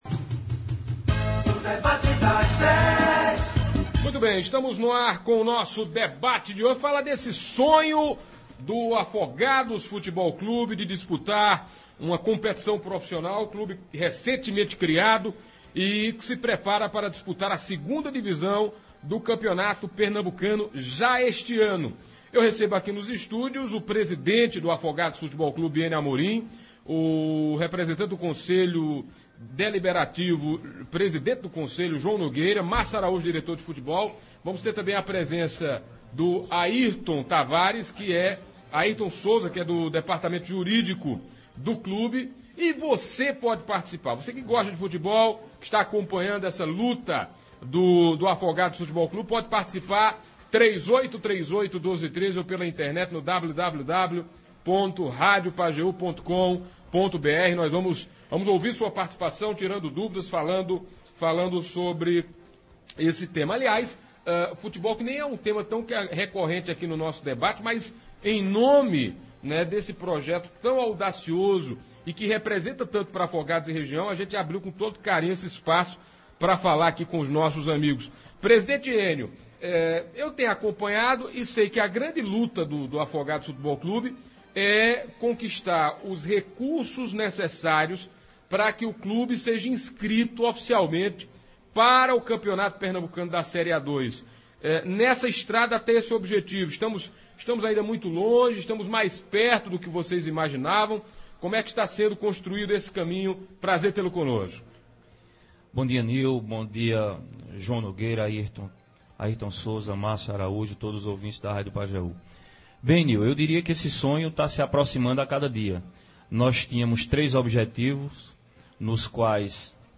Debate das Dez: o futuro do futebol profissional no Pajeú – Rádio Pajeú